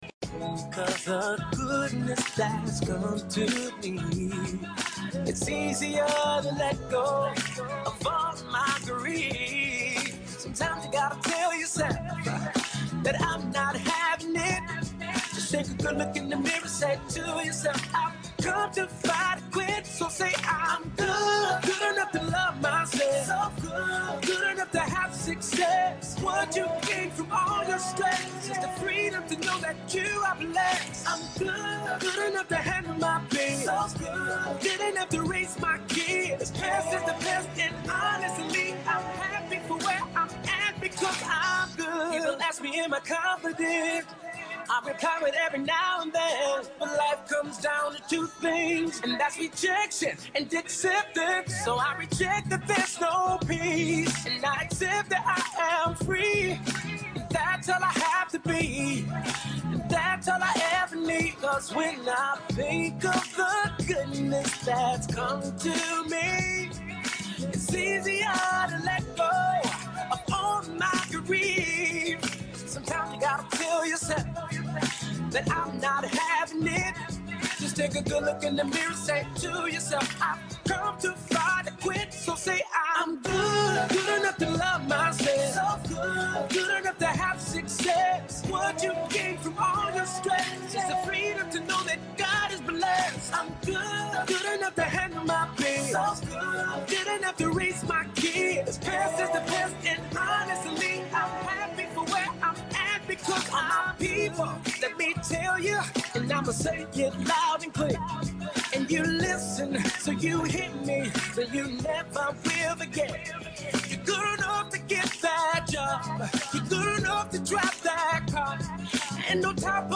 IMPOSSIBLE MISSION FOUNDATION EASTER SERIES SAVE THE JEWEL! 1ST SERVICE